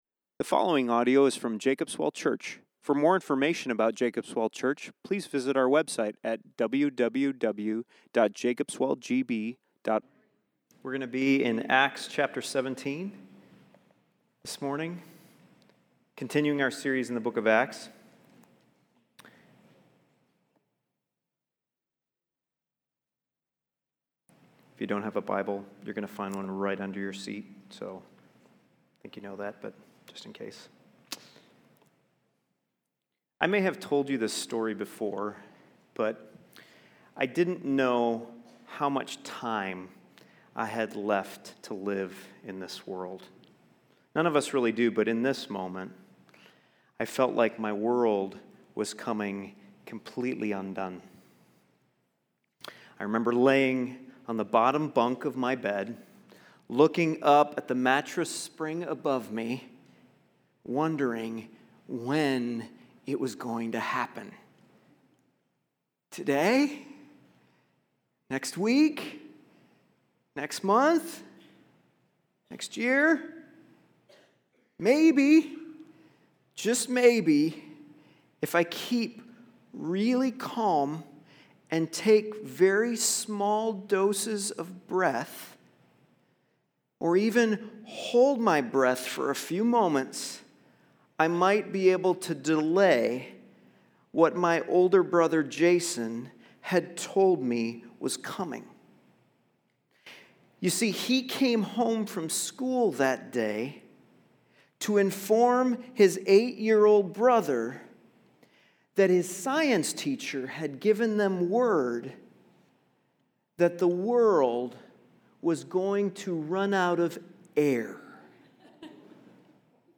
Sermon Detail
4_23_17_Sermon_Audio.mp3